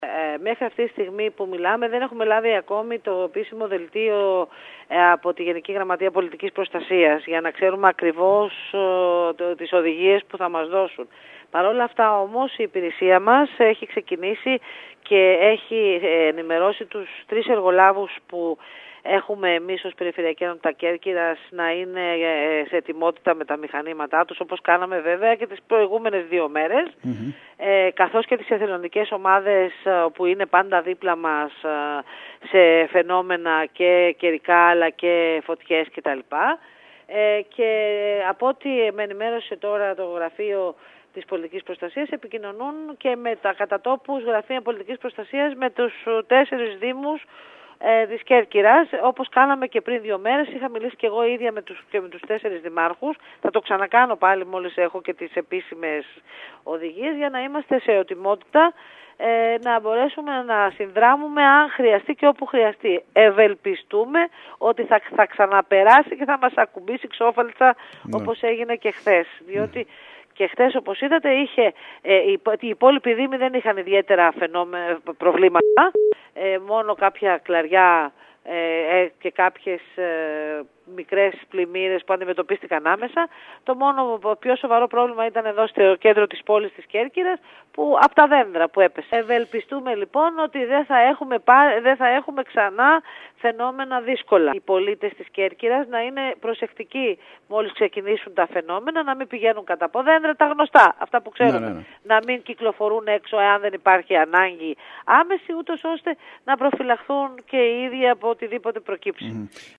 Σε ετοιμότητα βρίσκεται ο μηχανισμός πολιτικής προστασίας της Π.Ε. Κέρκυρας, εξαιτίας του επερχόμενου κύματος κακοκαιρίας που αναμένεται τα ξημερώματα του Σαββάτου. Η Αντιπεριφερειάρχης Μελίτα Ανδριώτη ανέφερε ότι έχει γίνει συνεννόηση τόσο με τους εργολάβους που διαθέτουν τα μηχανήματά τους σε ακραία φαινόμενα στην Κέρκυρα όσο και με τις εθελοντικές ομάδες που βοηθούν κατά τη διάρκεια έκτακτων γεγονότων και καταστροφών, ώστε το νησί να μην βρεθεί απροετοίμαστο.